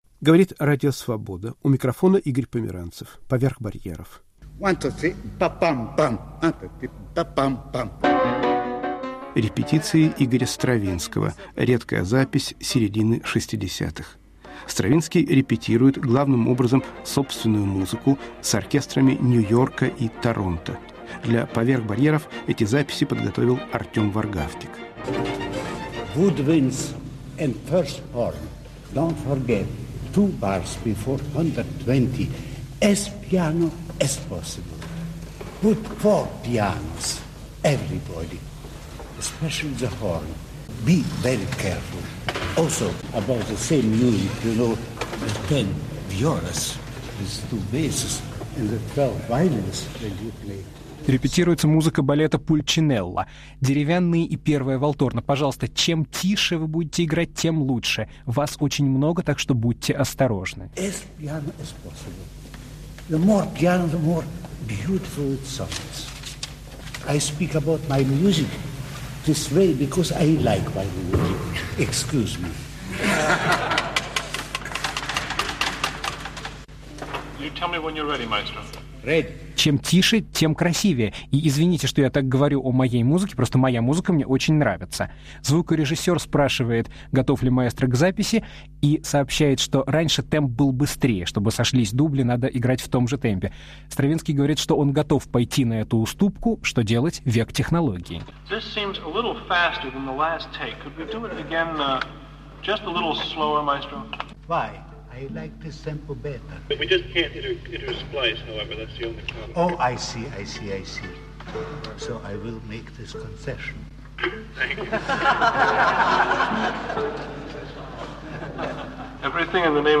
Репетиции Игоря Стравинского
с оркестрами Нью-Йорка и Торонто. Редкие записи середины 60-х годов прошлого века